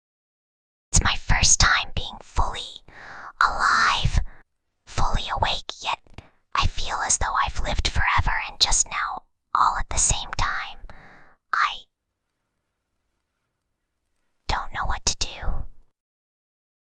Whispering_Girl_2.mp3